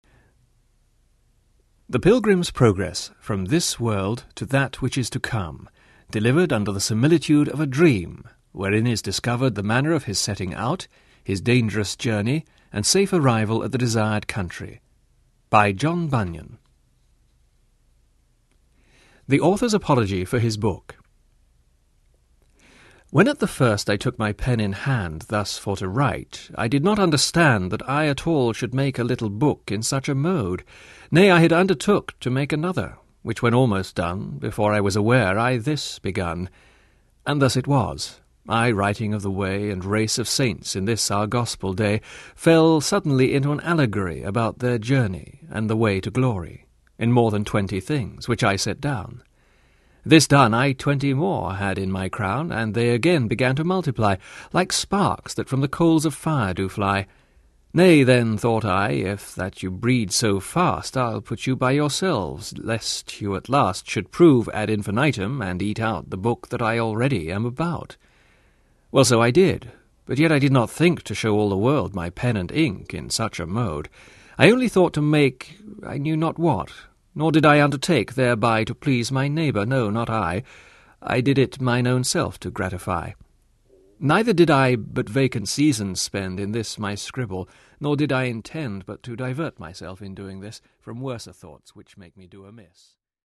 The Pilgrim’s Progress Audiobook
Narrator
9 Hrs. – Unabridged